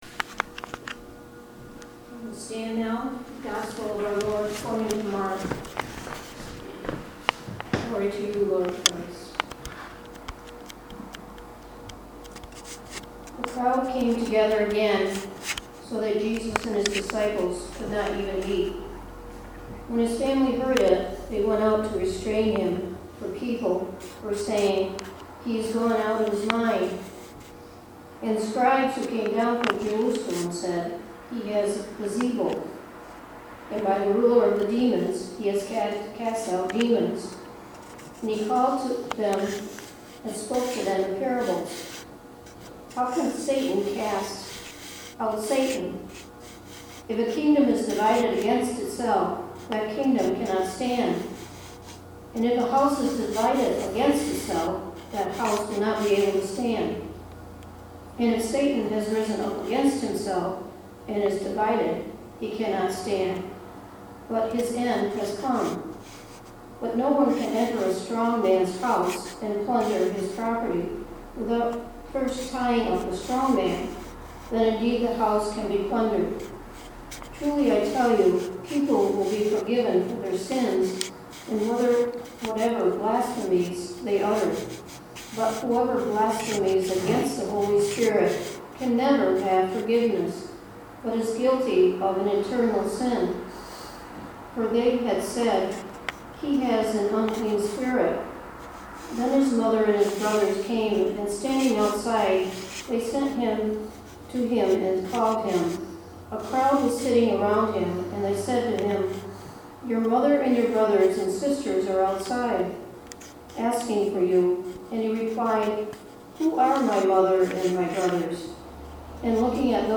But I Want It Really Really Bad – guest speaker